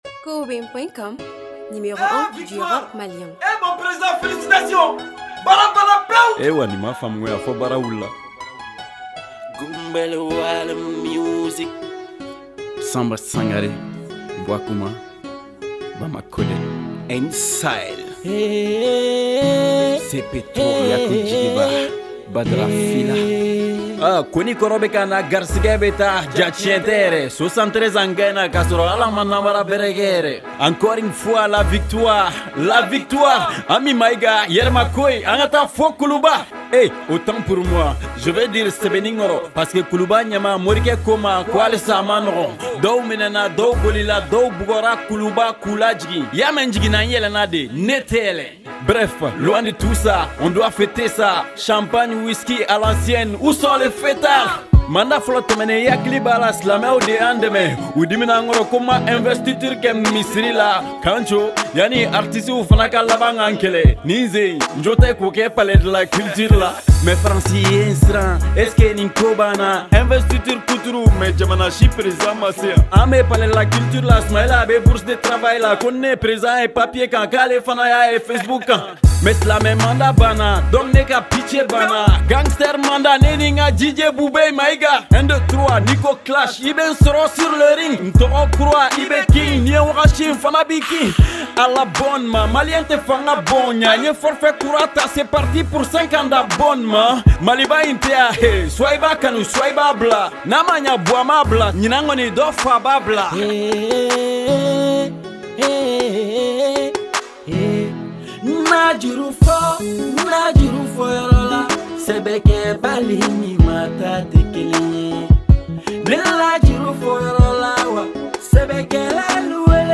musique Mali rap hip-hop